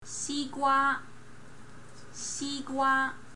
吃西瓜
描述：吃西瓜
声道立体声